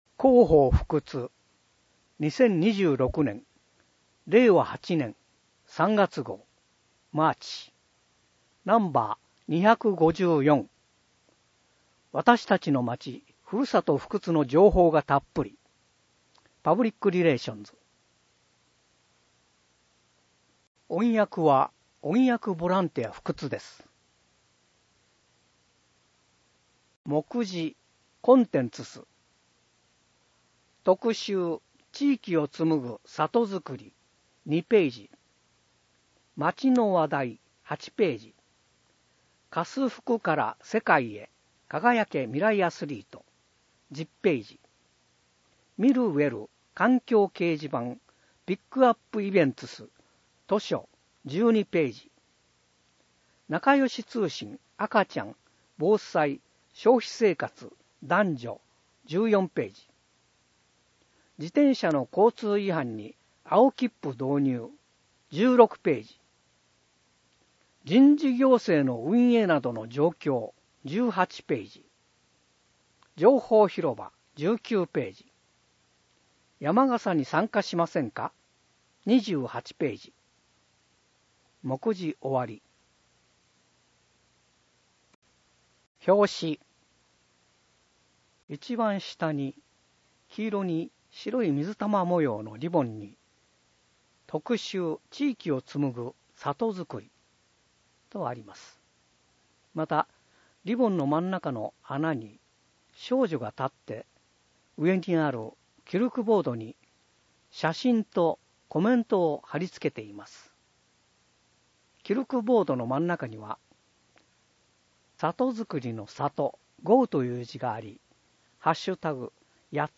音訳ボランティアふくつの皆さんが、毎号、広報ふくつを音訳してくれています。